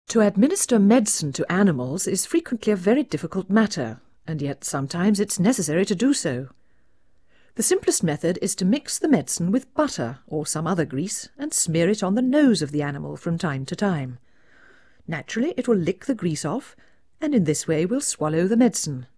Play 16 dB SNR Noise Mono
Noise in-phase
speech slightly distorted